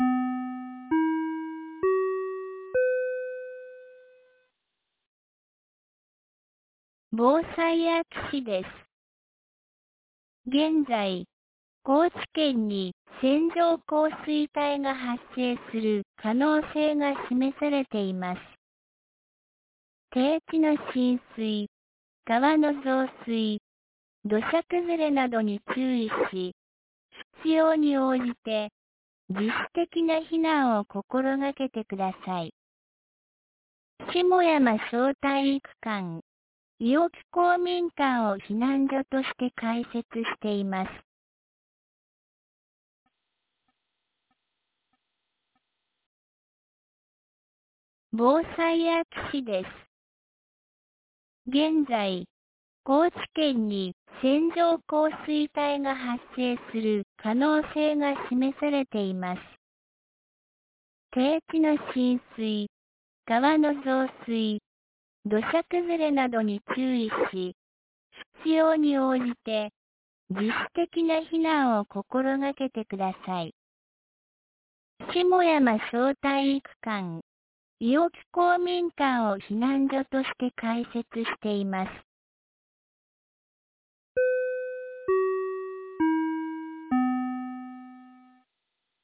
2024年05月28日 10時10分に、安芸市より伊尾木、下山へ放送がありました。